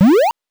powerup_10.wav